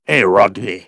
synthetic-wakewords
ovos-tts-plugin-deepponies_Luna_en.wav